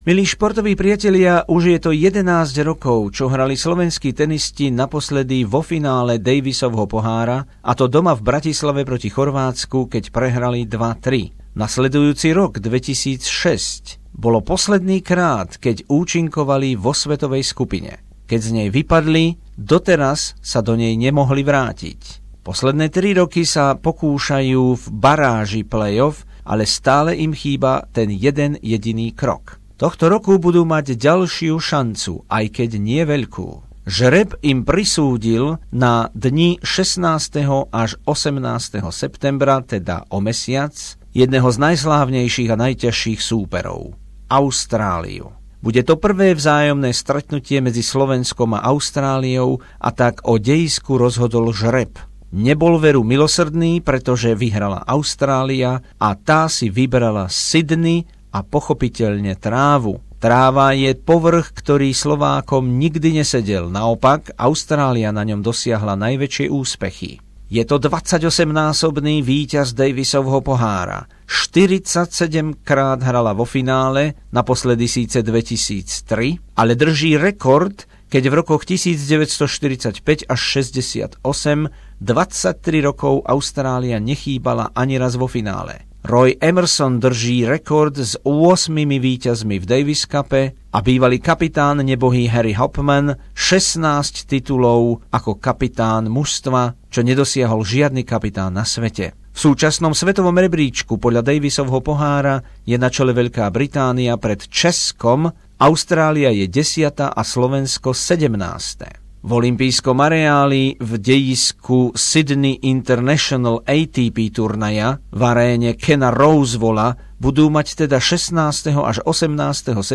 Rozšírená správa o blížiacom sa tenisovom daviscupovom stretnutí Austrália - Slovenslko v Sydney 16.-18. septembra 2016 v Aréne Kena Rosewalla